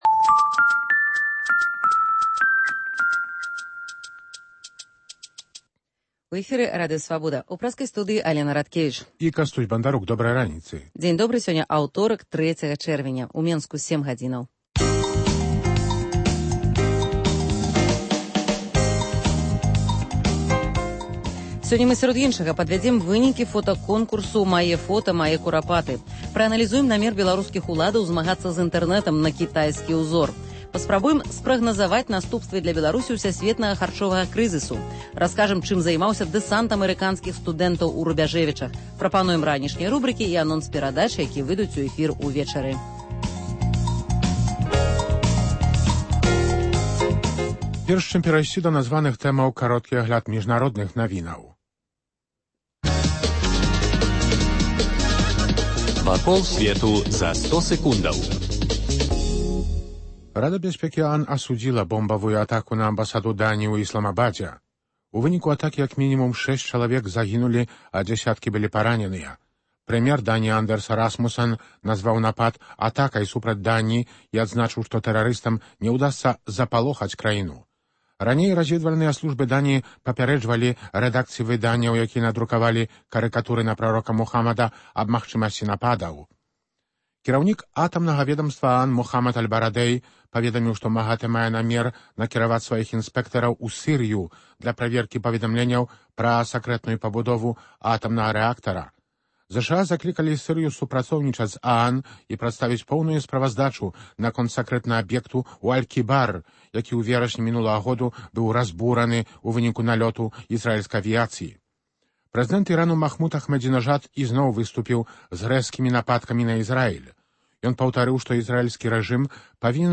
Ранішні жывы эфір
У Беларусі яны аднаўлялі закінутыя могілкі габрэяў, расстраляных гітлераўцамі * Пачалося лета. Як адпачыць інвалідам? * Інтэрвію са Сьвятланай Алексіевіч